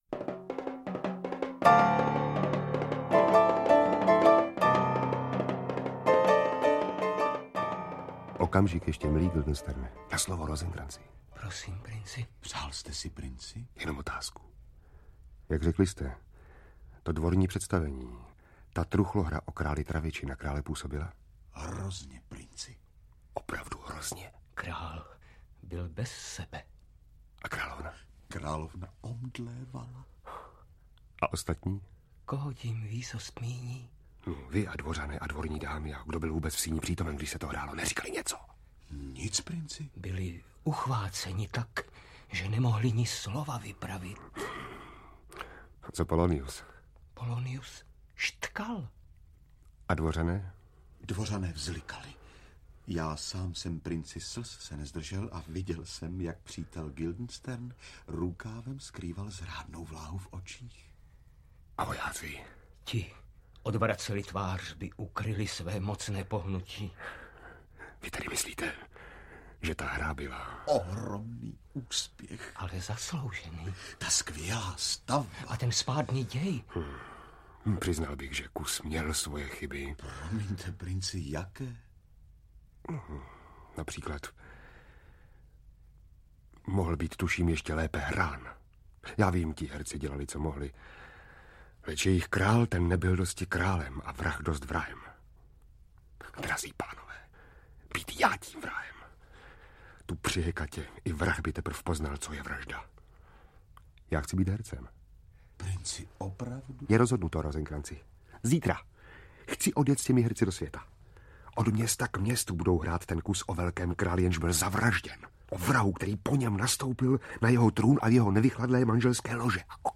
Pásmo Jana Kopeckého "Svět podle Karla Čapka" - Karel Čapek, Jan Kopecký - Audiokniha
• Čte: Otakar Brousek st., Ota Sklenčka,…